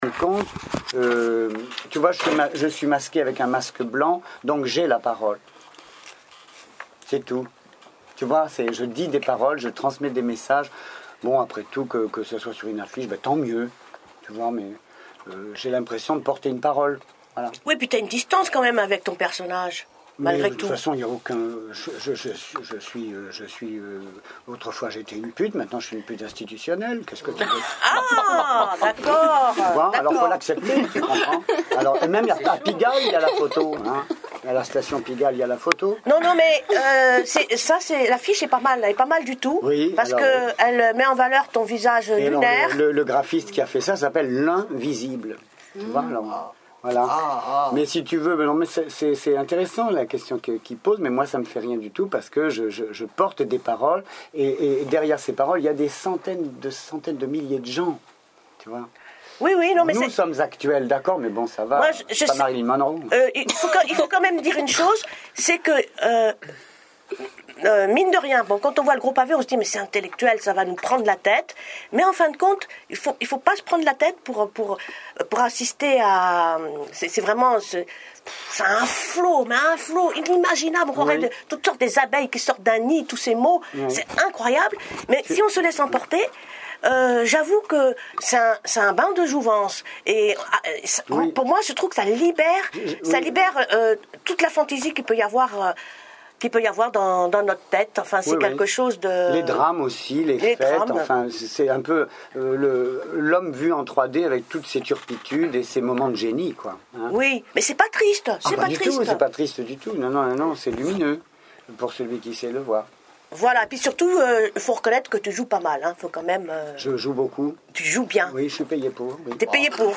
EXTRAIT INTERVIEW